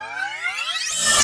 07308 data access void noise
access computer data digital glitch harsh noise void sound effect free sound royalty free Gaming